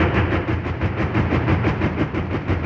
RI_DelayStack_90-07.wav